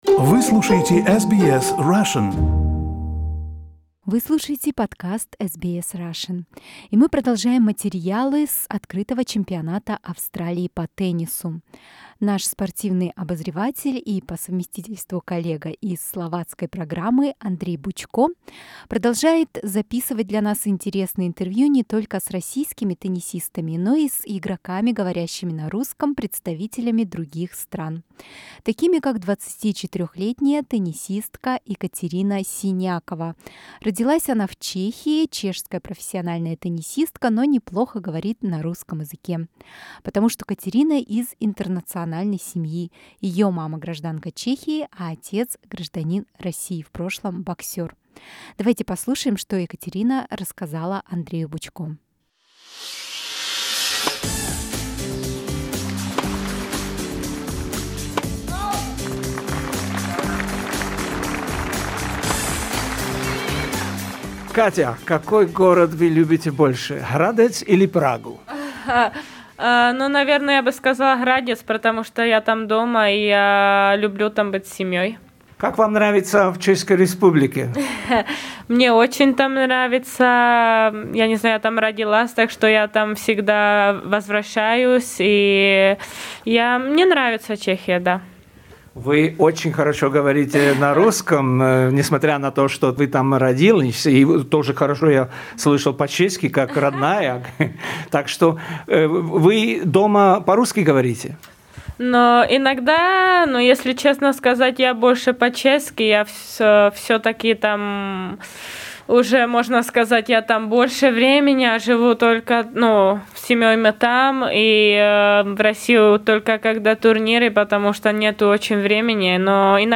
Interview with Czech tennis player Kateřina Siniaková at the Australian Open